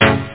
Amiga 8-bit Sampled Voice
synth6.mp3